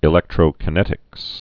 (ĭ-lĕktrō-kə-nĕtĭks, -kī-)